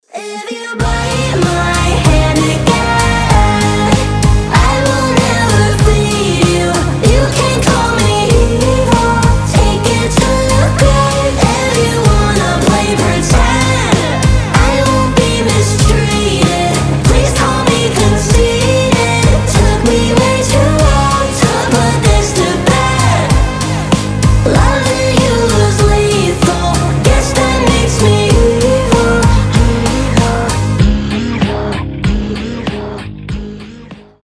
alternative
поп-панк